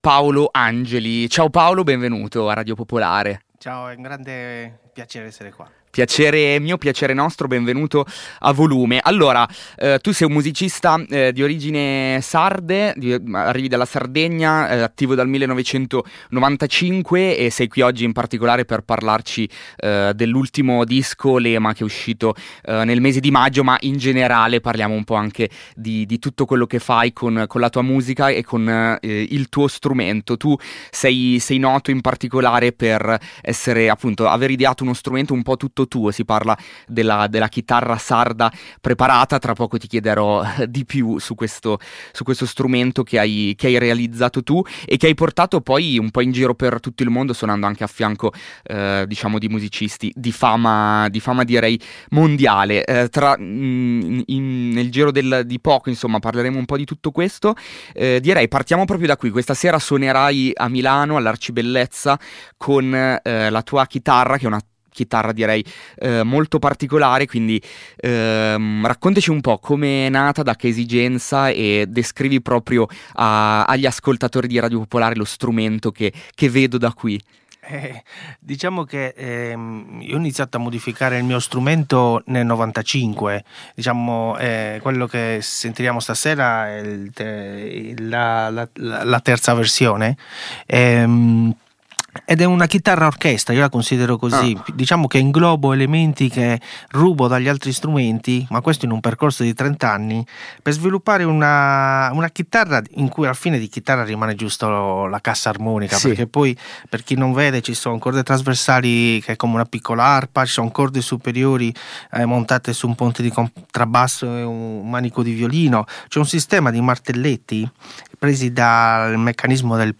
25 corde, ibrido tra chitarra baritono, violoncello e batteria, dotato di martelletti, pedaliere ed eliche
è passato in studio con la sua chitarra per presentarci il suo ultimo album
l’intervista e il MiniLive